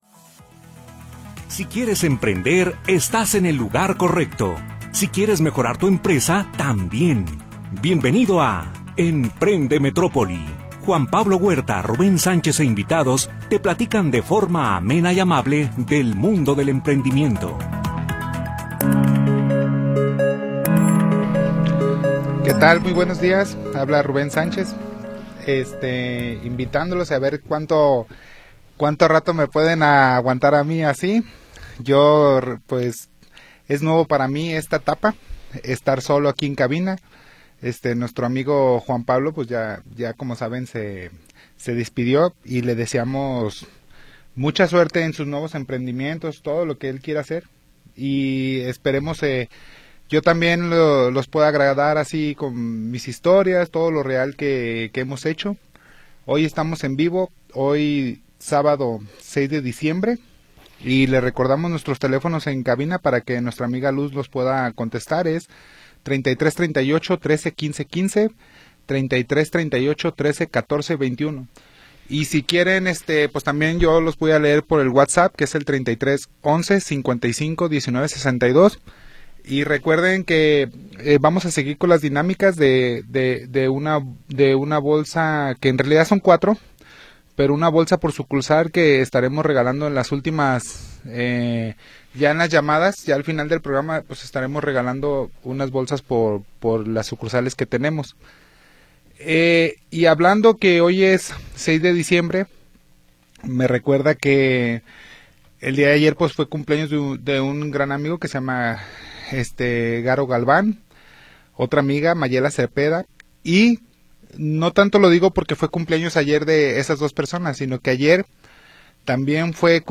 te platican de forma amable y amena acerca del mundo del emprendimiento. Programa transmitido el 6 de Diciembre de 2025.